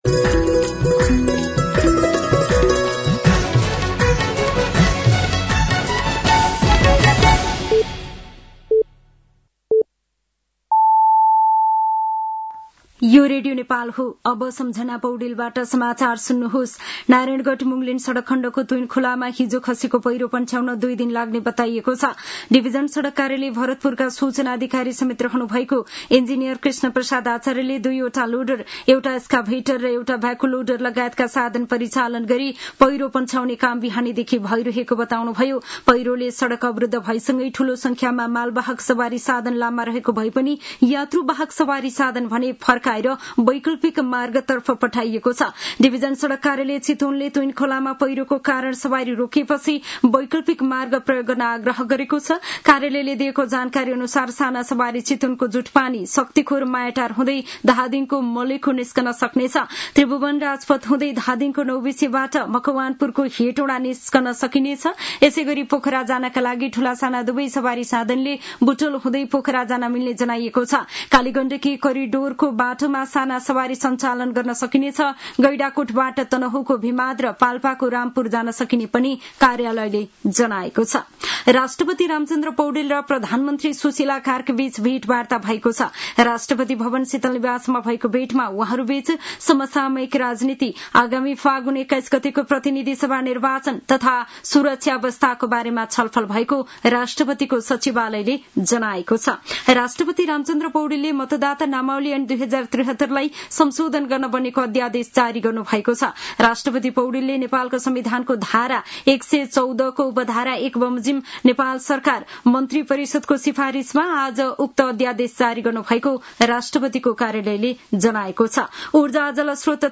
साँझ ५ बजेको नेपाली समाचार : ८ असोज , २०८२